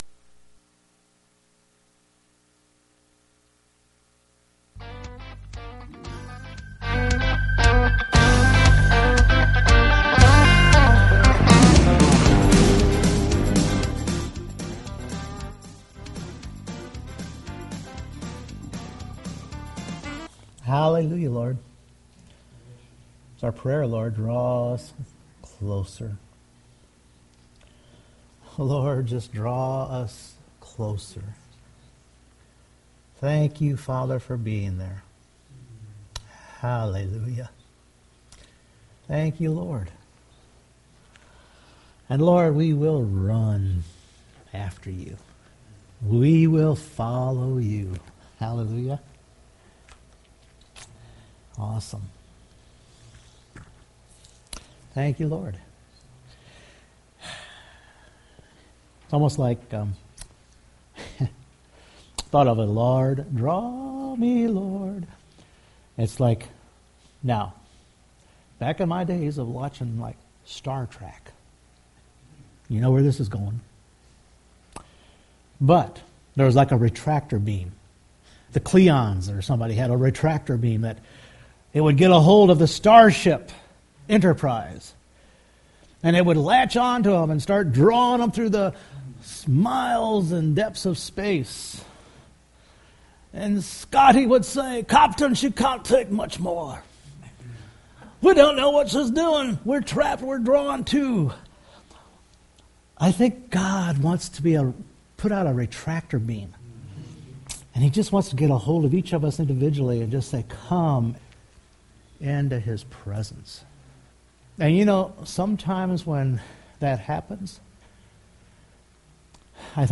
2 Timothy 4:16-18 Service Type: Sunday Morning The Lord stood with and never abandoned Paul